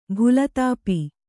♪ bhulatāpi